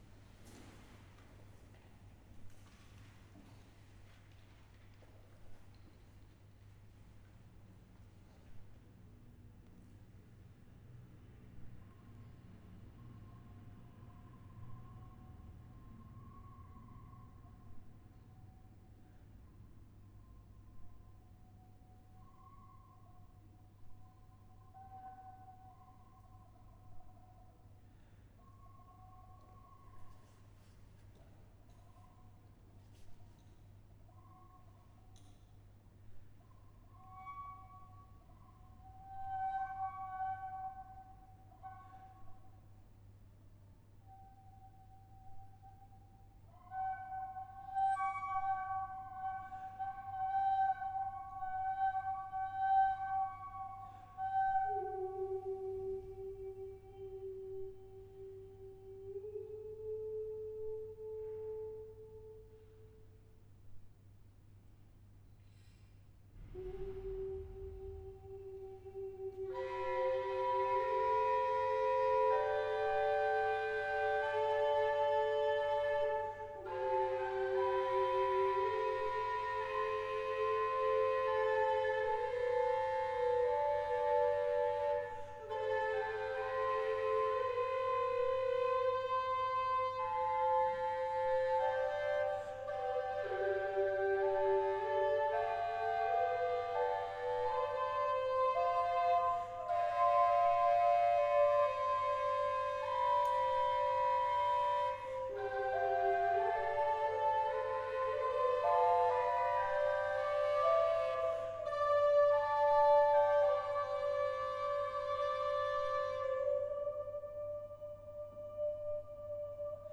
My first piece, experimenting with multiphonics in 2 instruments simultaneously (end) and labium manipulation resulting in a frulato-like effect.